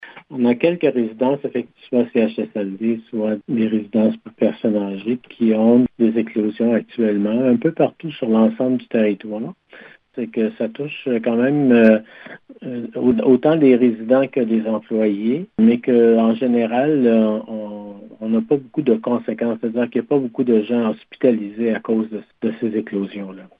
Le directeur de la santé, Yv Bonnier-Viger précise qu’autant des résidents que des travailleurs sont touchés par le virus :